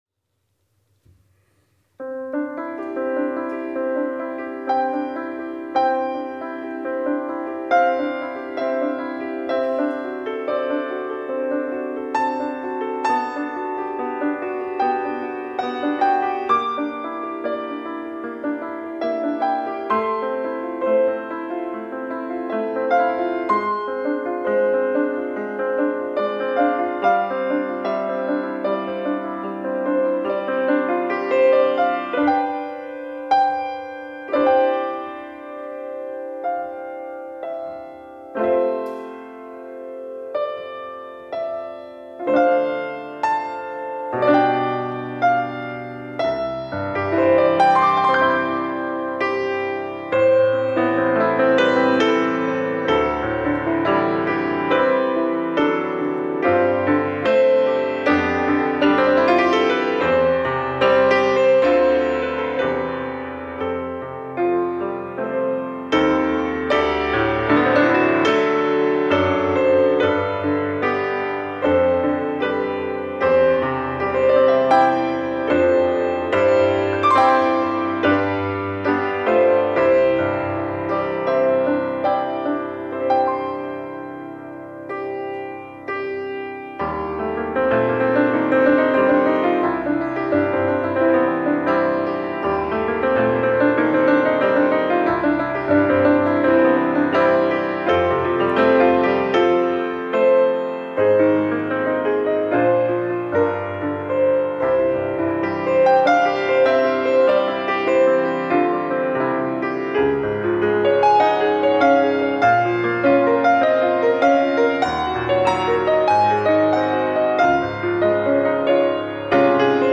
특송과 특주 - 내 평생에 가는 길